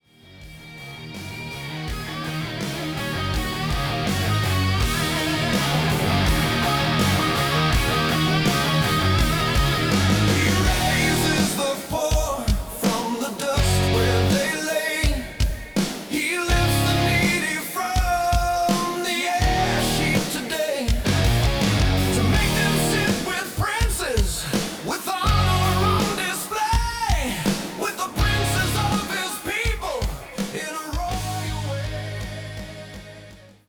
Heavy electric guitar riffs and soaring solos
Driving bass lines that anchor each song
Distinctive rock drumming with dynamic fills
Raw, authentic Southern rock vocals